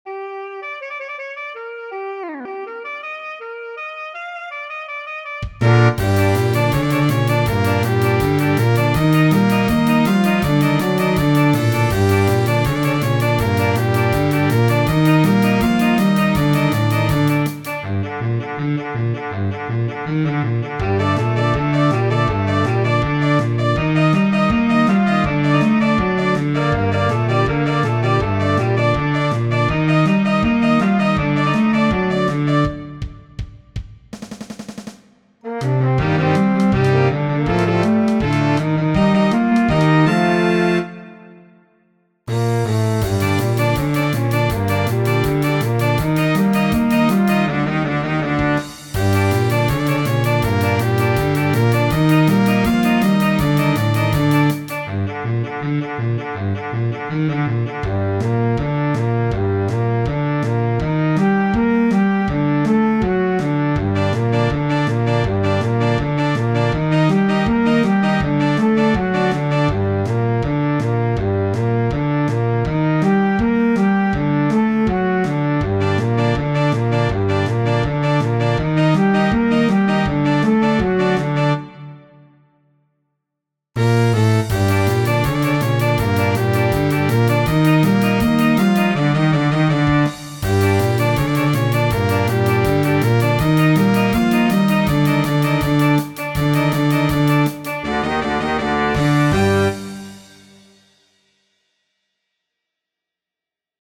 Verse Verse Chorus Solo Solo Chorus
key Bb